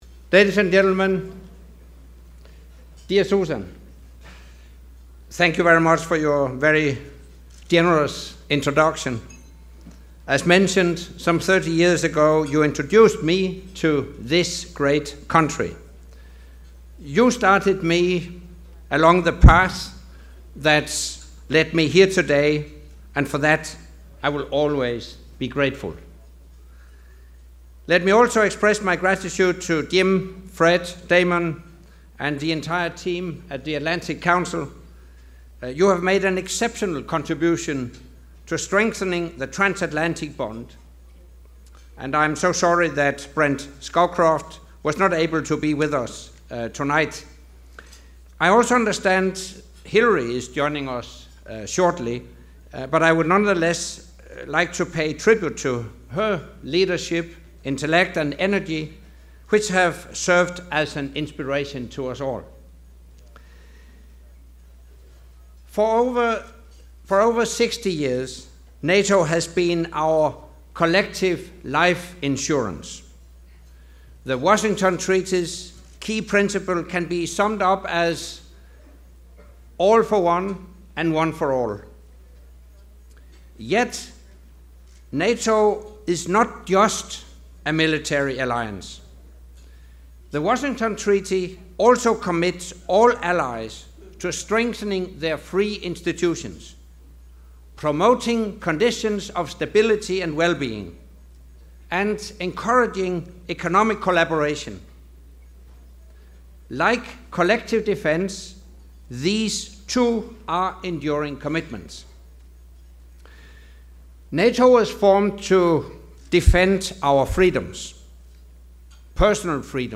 ''Striking a new transatlantic deal'' - Acceptance speech by NATO Secretary General Anders Fogh Rasmussen at the Atlantic Council of the United States’ Distinguished leadership award, in Washington, DC
Speech by NATO Secretary General Anders Fogh Rasmussen at the Distinguished Leadership Award organised by the US Atlantic Council